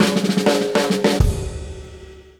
To the left you see an image of a drum roll.
(the high output gain was necessary to keep the peak value at -2,9 dB)